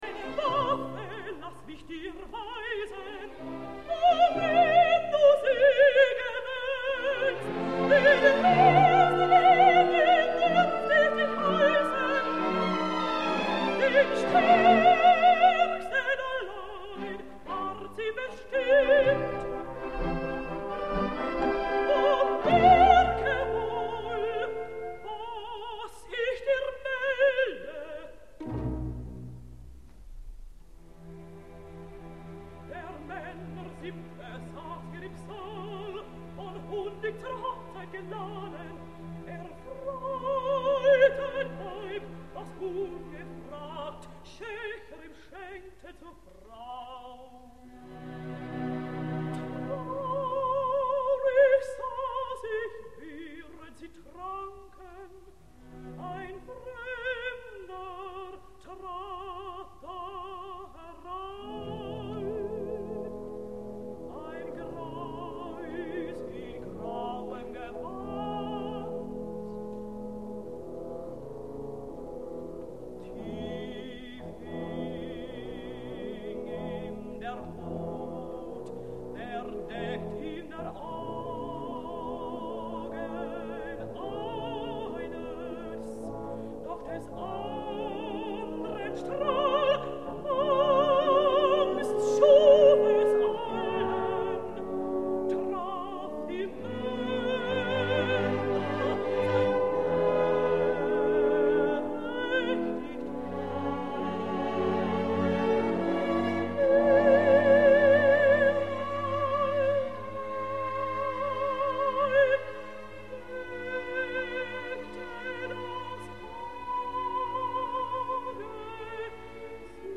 Absolutament colpidora.